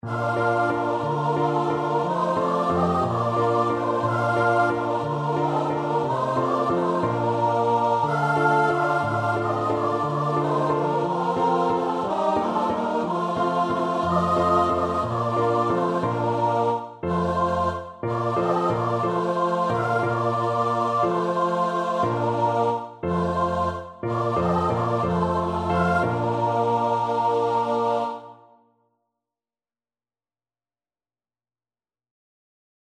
3/4 (View more 3/4 Music)
Choir  (View more Easy Choir Music)
Traditional (View more Traditional Choir Music)